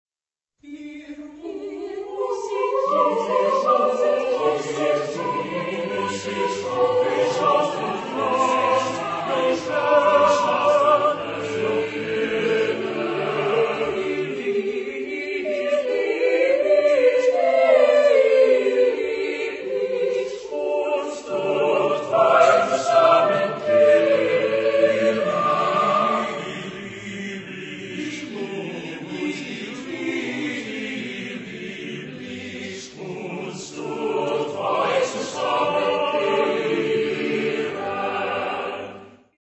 Genre-Style-Forme : Madrigal ; Profane
Type de choeur : SSATTB  (6 voix mixtes )
Tonalité : la majeur